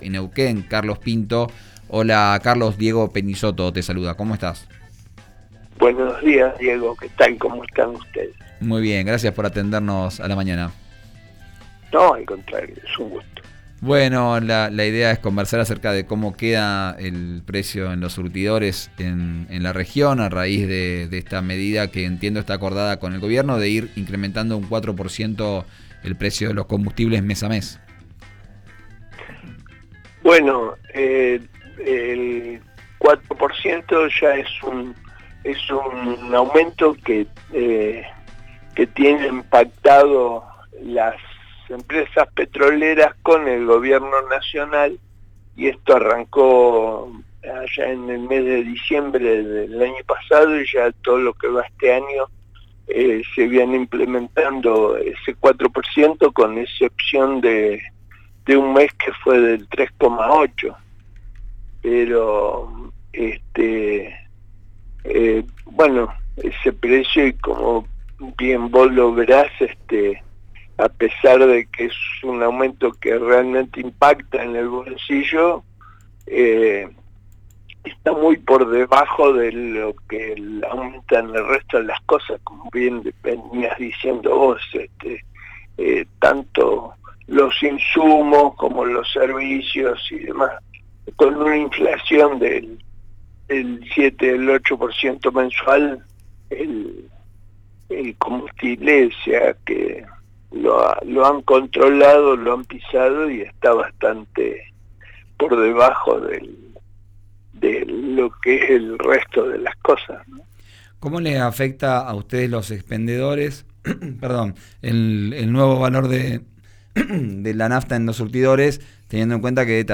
en diálogo con «Vos al aire» por RÍO NEGRO RADIO.